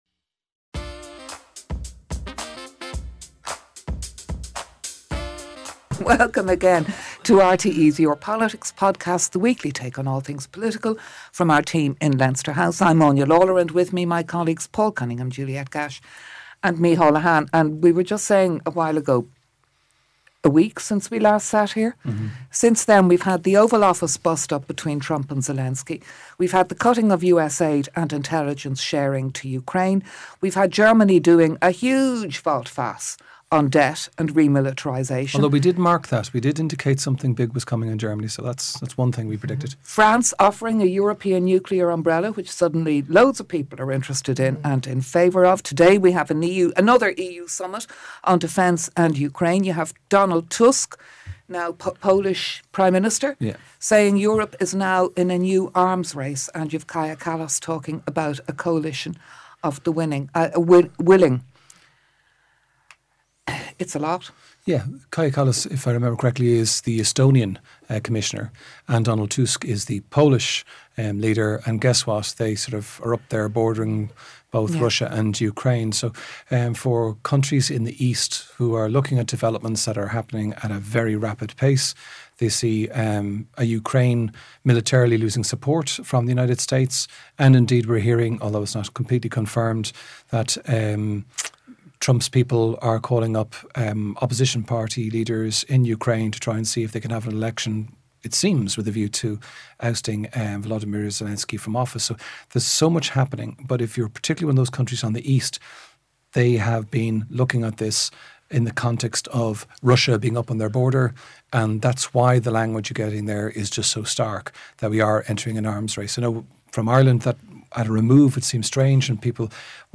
The RTÉ team at Leinster House and guests have an informal discussion about what has been happening in the world of Irish politics.